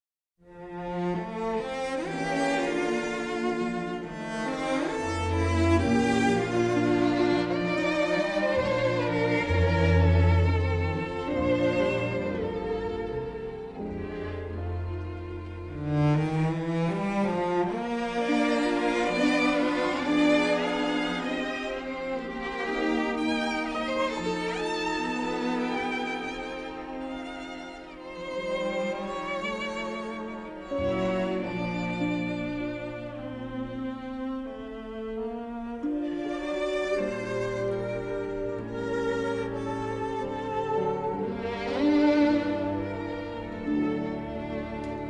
muted, atmospheric cues
remastered in stereo from the original three-track masters